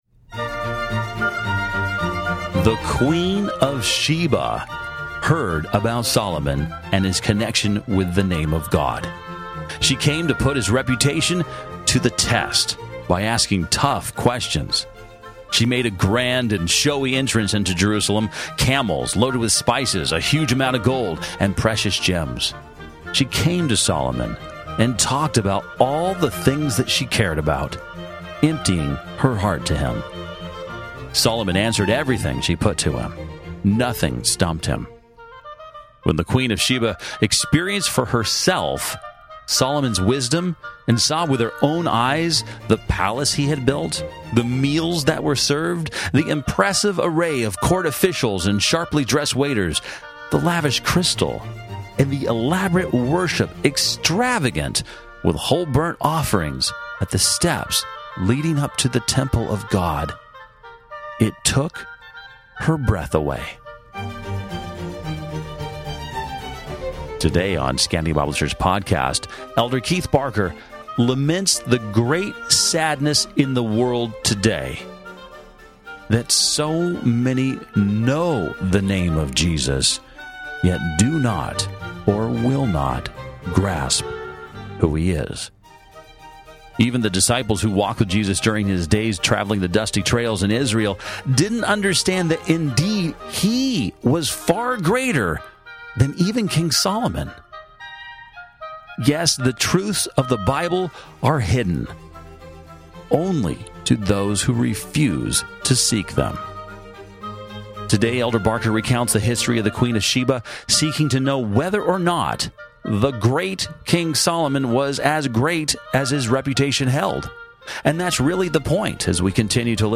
Sermon Notes Date